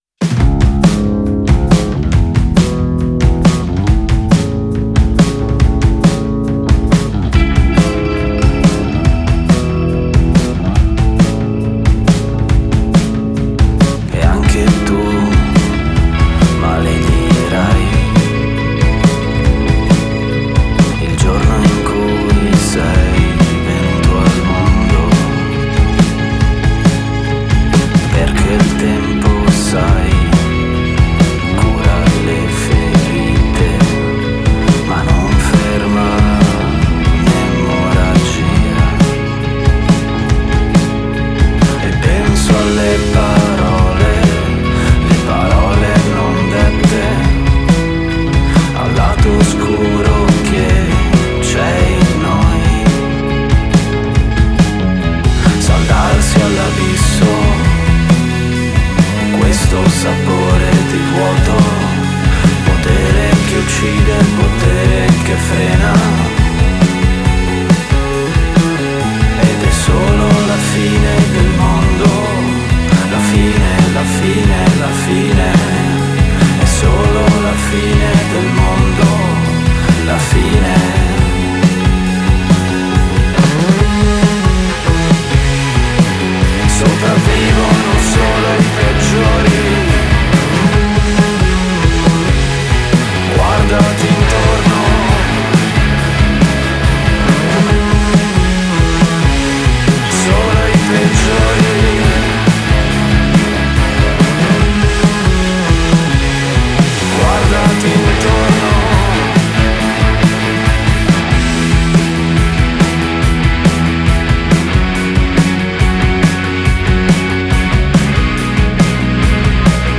gruppo post-punk